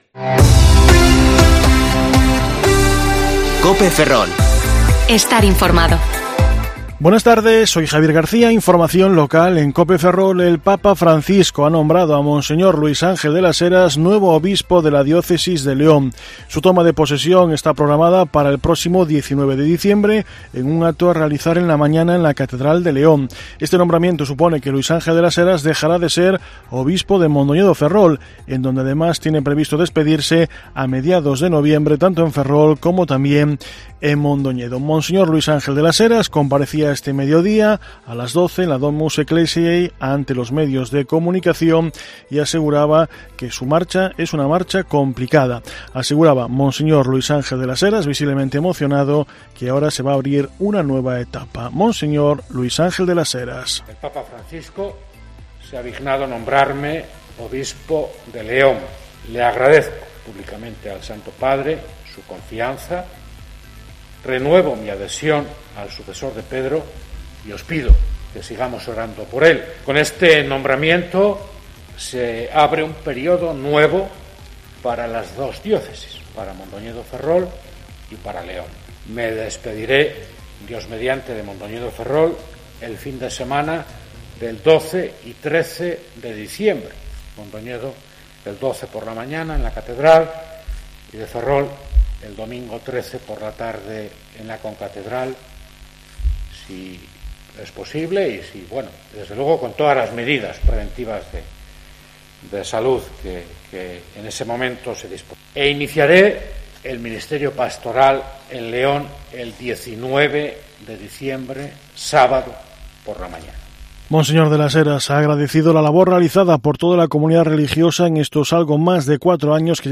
Informativo Mediodía COPE Ferrol 21/10/2020 (De 14,20 a 14,30 horas)